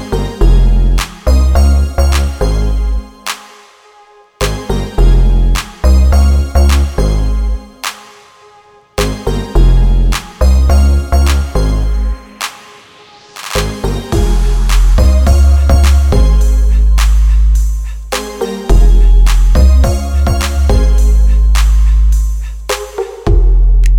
for solo female Pop